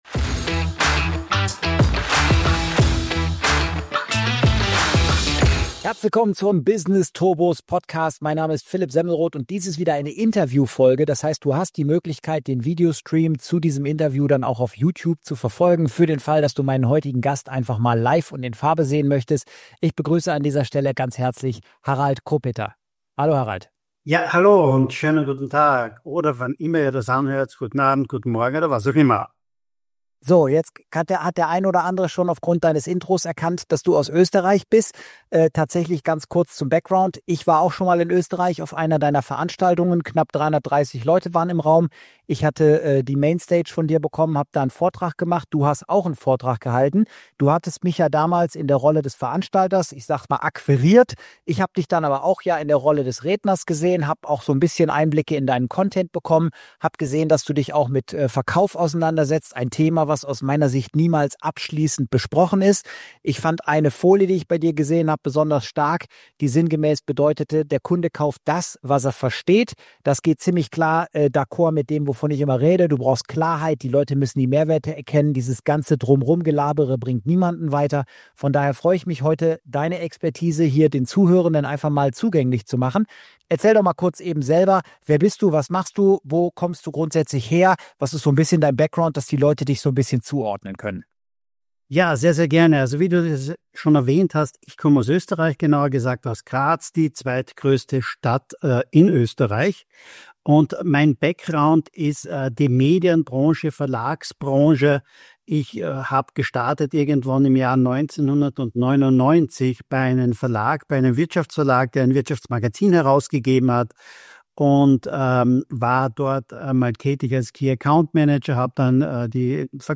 #94 - INTERVIEW